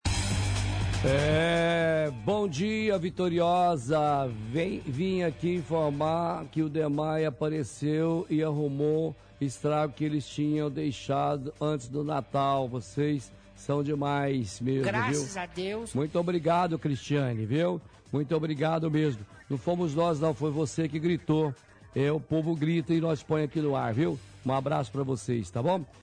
lê mensagem de ouvinte